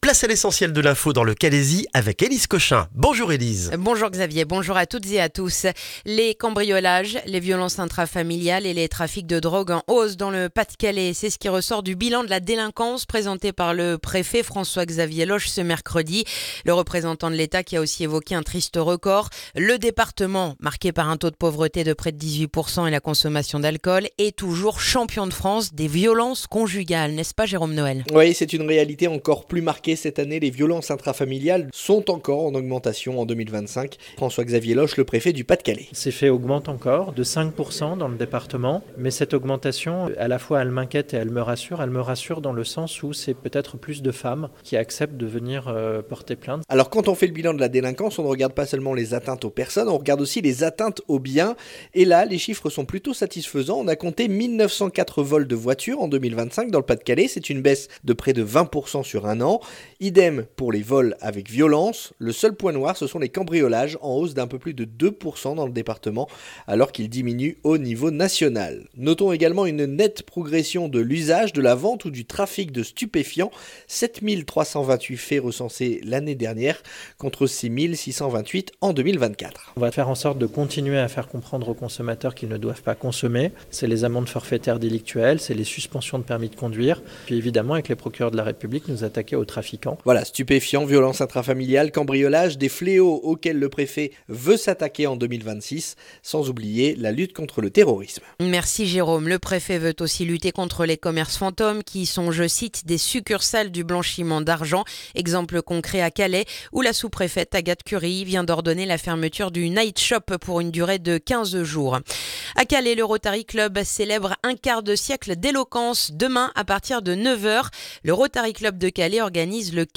Le journal du vendredi 6 février dans le calaisis